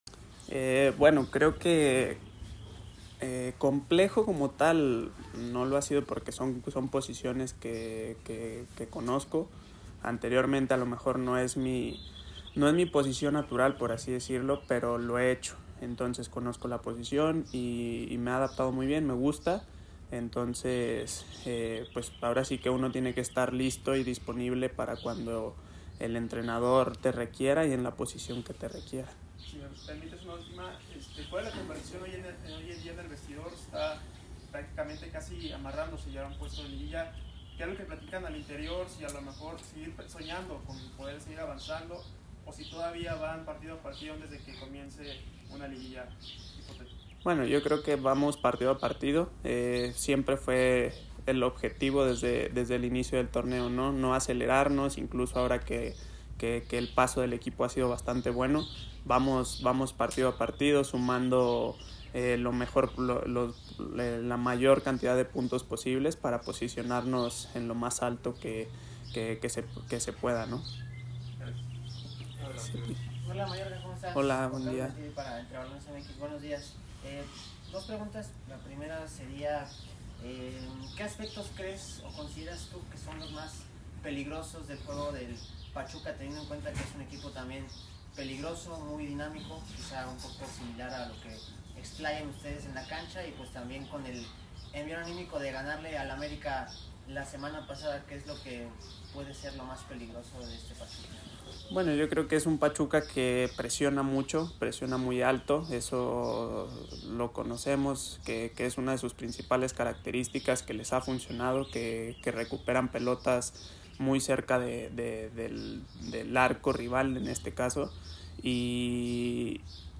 Estás fueron las declaraciones del defensor de Club Necaxa en el día de medios en Casa Club, previo a recibir a Pachuca en el último partido como local del torneo regular